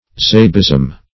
Zabism \Za"bism\, n.